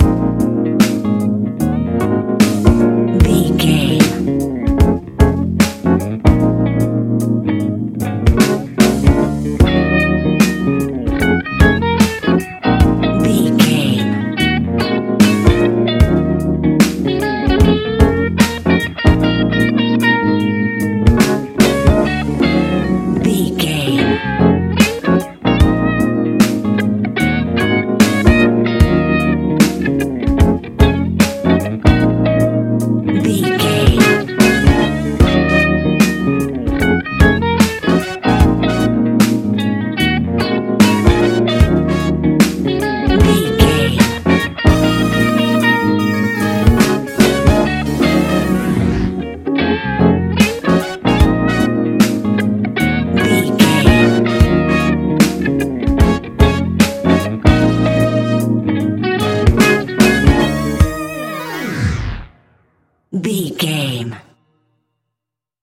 Fast paced
Uplifting
Ionian/Major
D♯